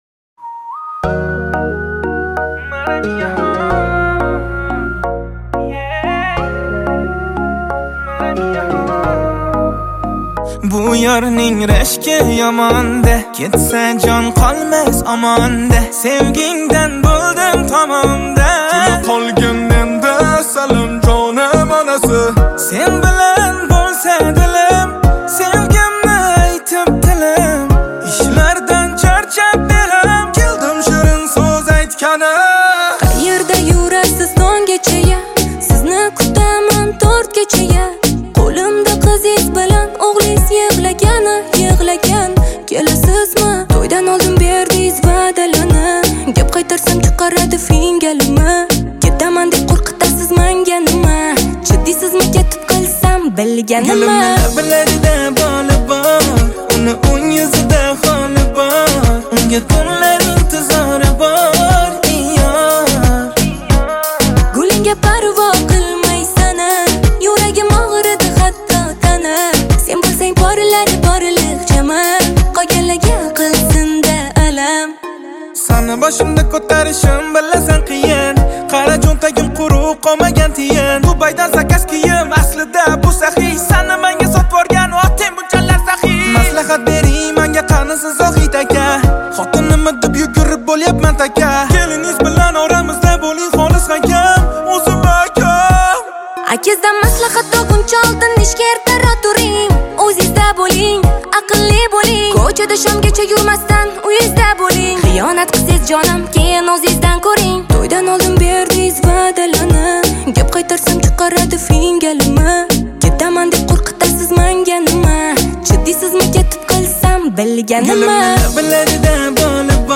• Категория: Узбекская музыка